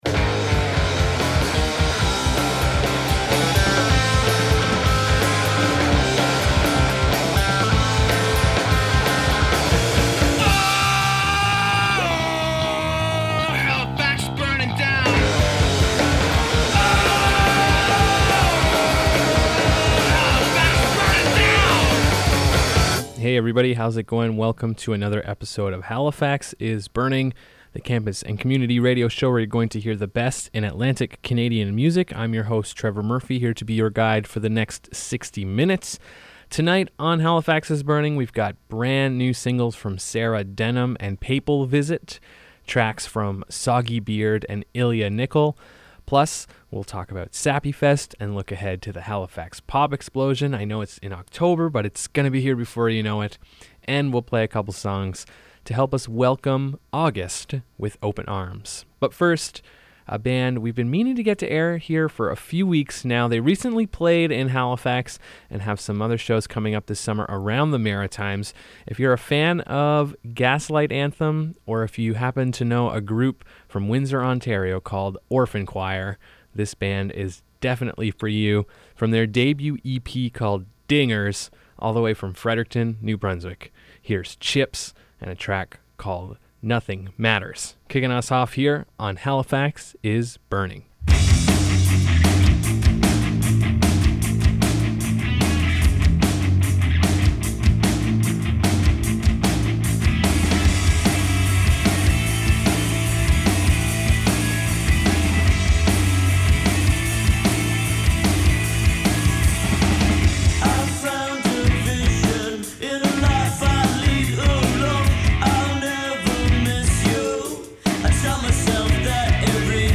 The best independent East Coast music.